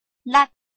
臺灣客語拼音學習網-客語聽讀拼-南四縣腔-入聲韻
拼音查詢：【南四縣腔】lad ~請點選不同聲調拼音聽聽看!(例字漢字部分屬參考性質)